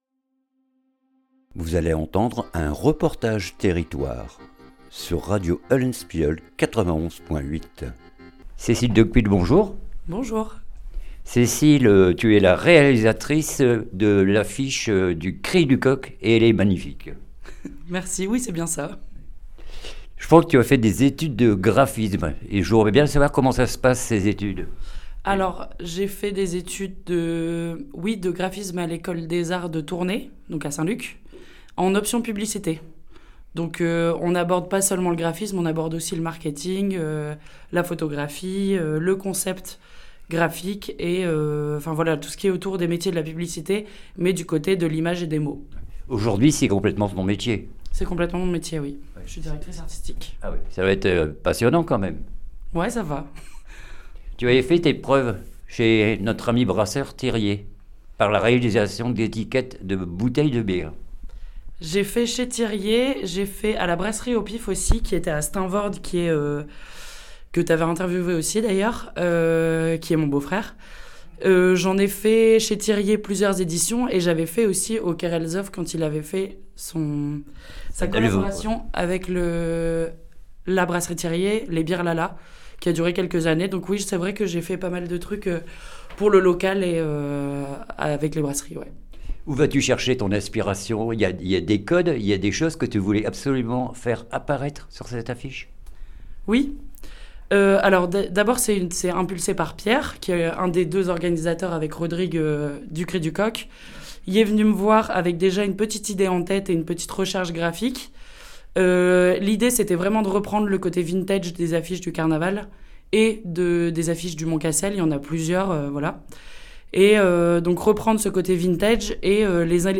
REPORTAGE TERRITOIRE L AFFICHE DU CRI DU COQ